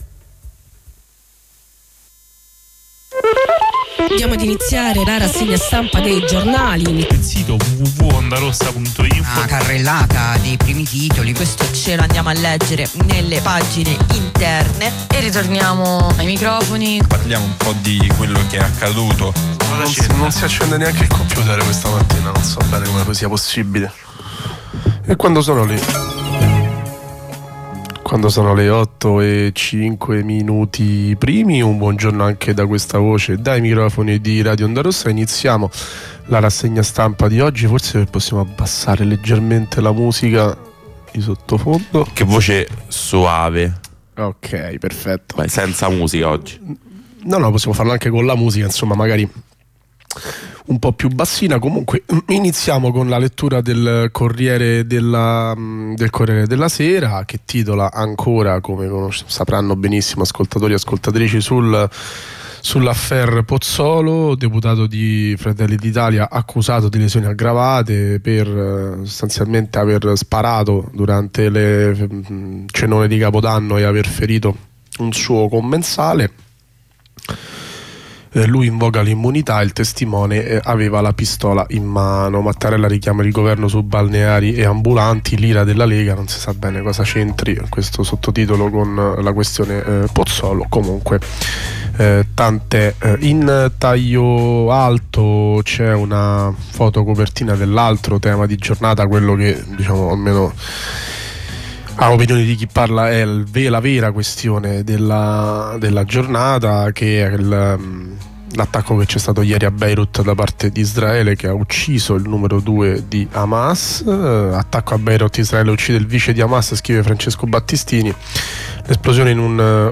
Lettura e commento dei quotidiani.
Rassegna stampa